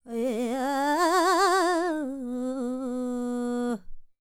QAWALLI 12.wav